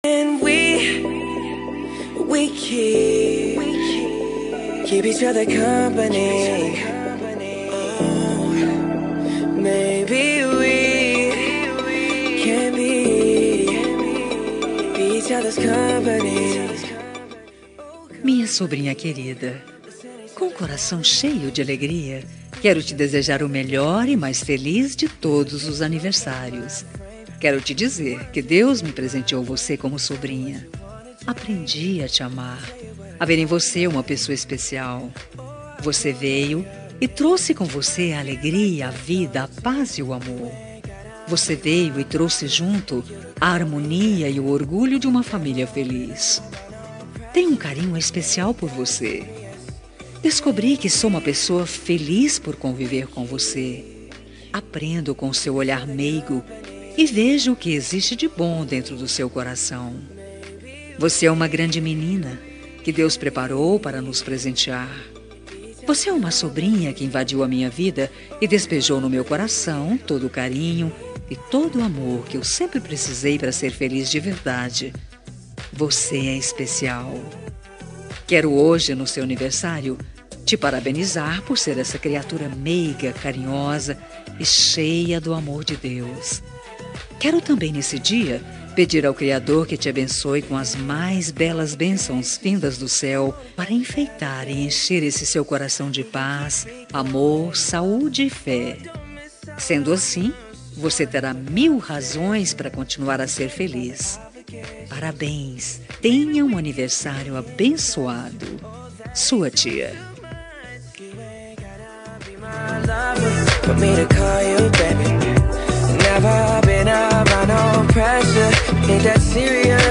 Aniversário de Sobrinha – Voz Feminina – Cód: 4283